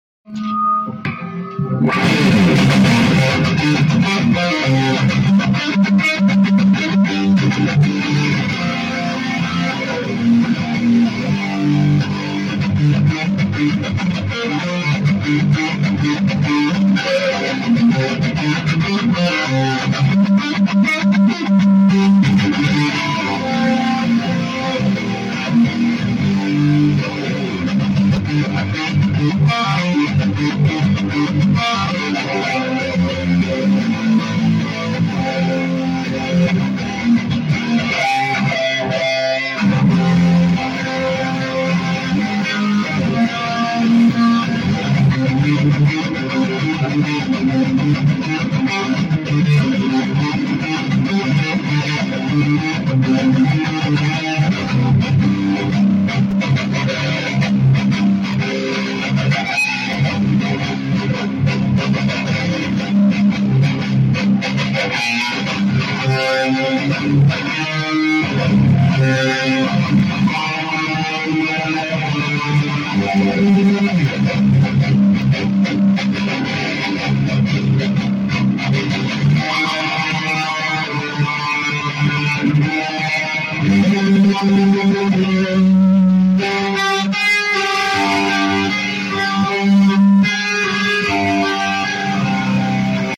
new amp Orange Super crush 100 100watt solo state amp head with Carvin 4x12 300 watt speaker cabinet